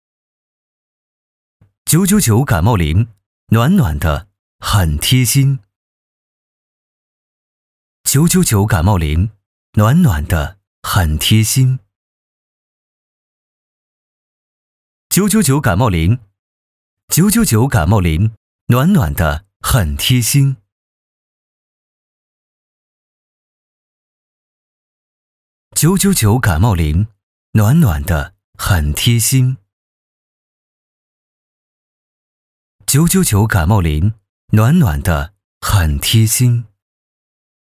男10号